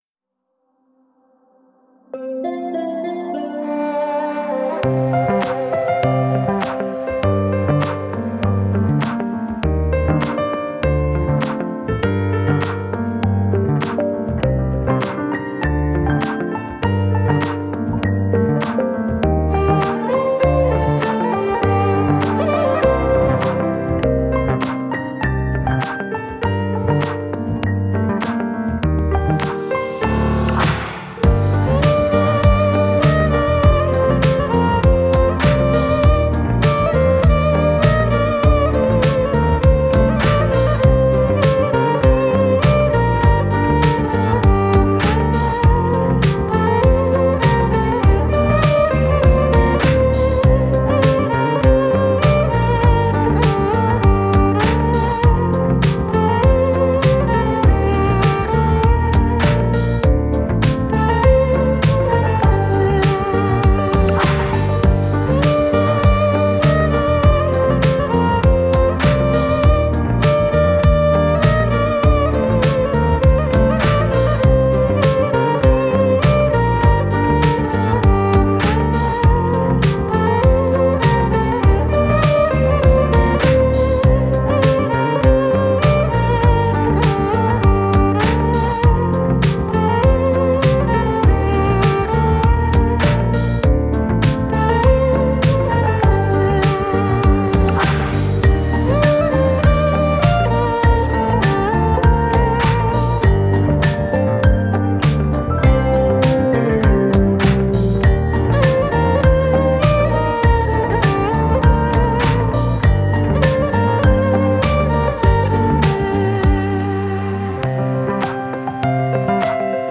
تک اهنگ ترکی
موزیک ترکی